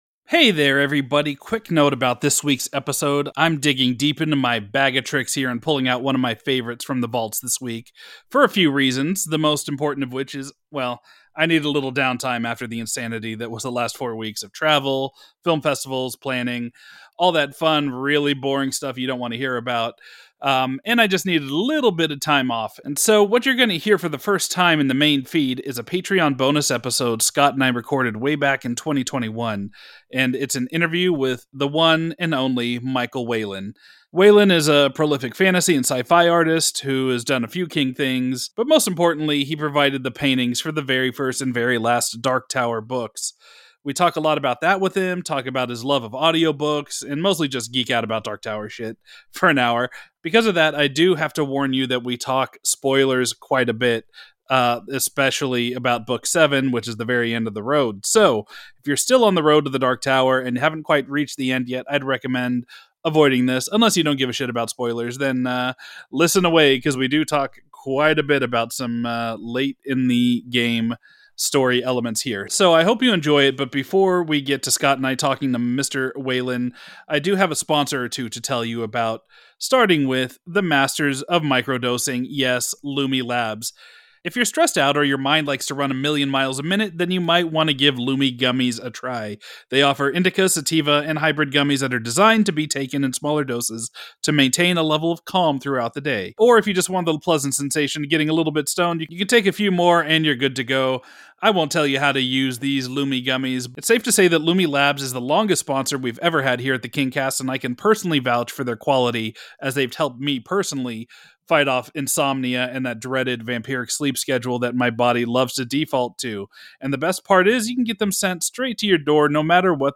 Flashback: An Interview with Michael Whelan